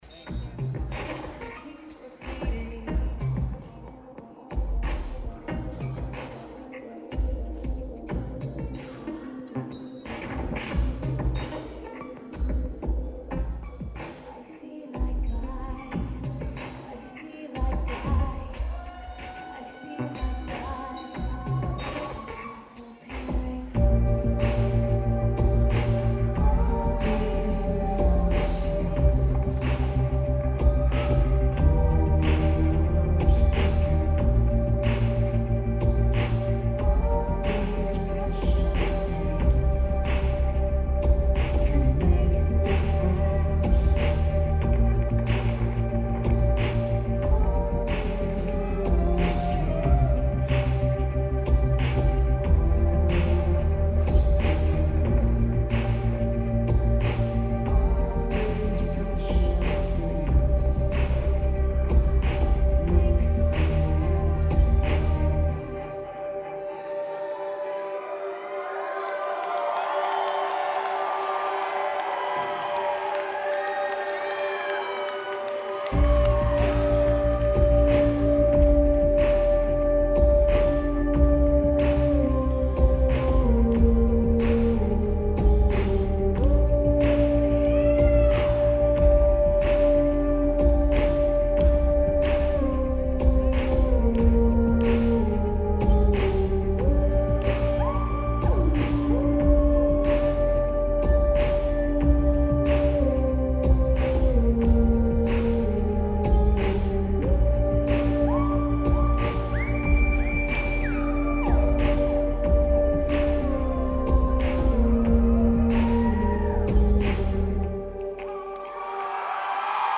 The Golden State Theatre